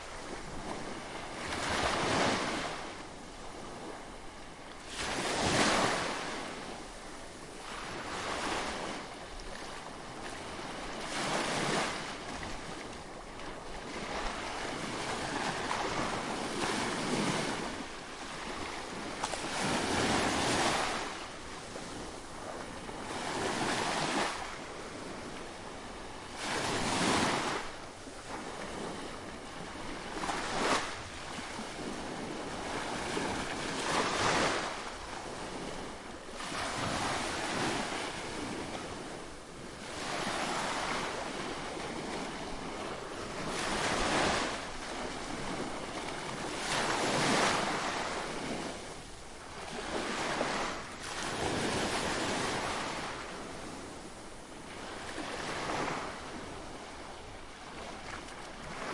平静的海浪II
描述：平静的海浪拍打。使用Zoom H1在2017年葡萄牙Olhão录制。最小的处理只是为了减少风低音隆隆声和增加收益。
标签： 海滩 WA VES 海洋 研磨 海边 海岸 现场录音
声道立体声